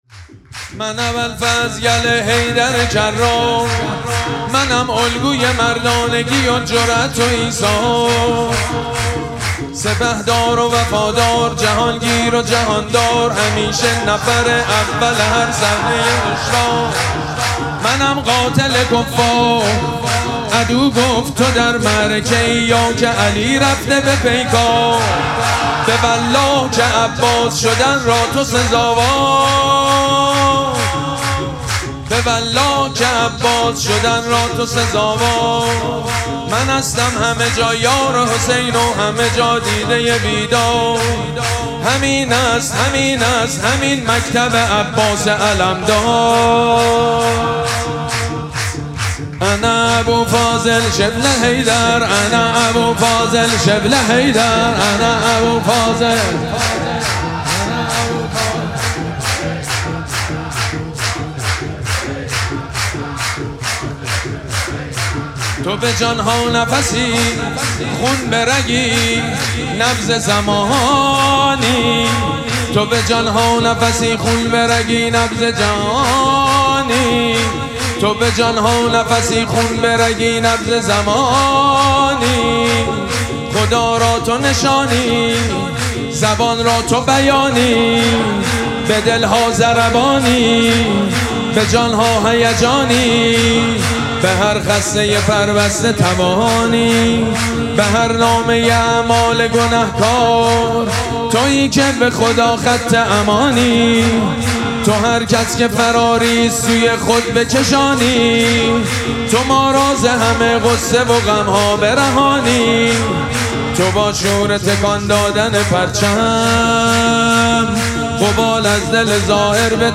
شب دوم مراسم جشن ولادت سرداران کربلا
حسینیه ریحانه الحسین سلام الله علیها
سرود